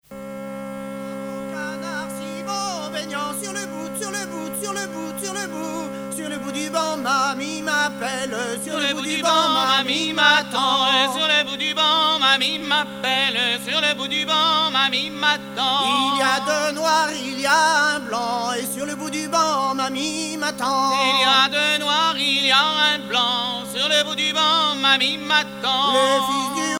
danse : an dro
Pièce musicale éditée